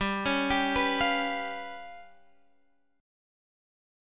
G7b5 chord